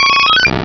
Cri d'Évoli dans Pokémon Rubis et Saphir.